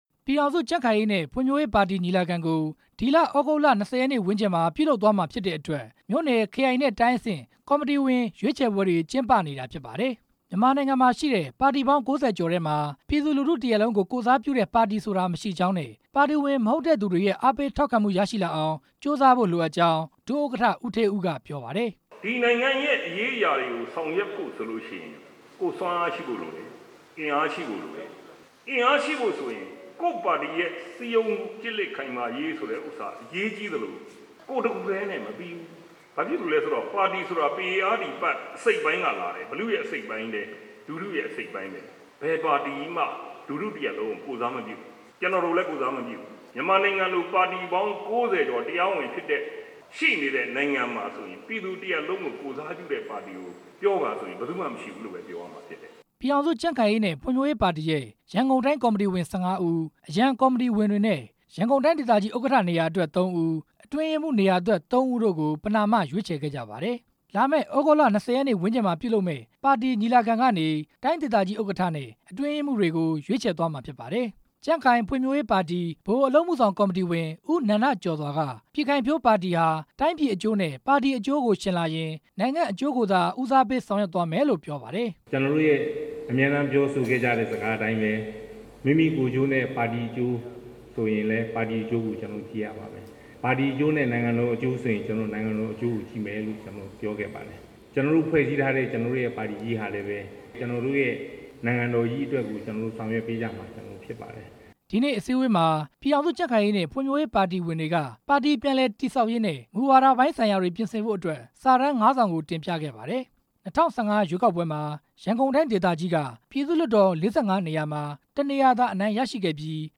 ဒီနေ့ ရန်ကုန်တိုင်း ပြည်ထောင်စု ကြံ့ခိုင်ရေးနဲ့ ဖွံ့ဖြိုးရေးပါတီရုံးချုပ်မှာပြုလုပ်တဲ့ တိုင်းဒေသကြီး ညီလာခံ အခမ်းအနားမှာ ပါတီဒုတိယဥက္ကဌ ဦးဌေးဦး က ပြောကြားလိုက်တာဖြစ်ပါတယ်။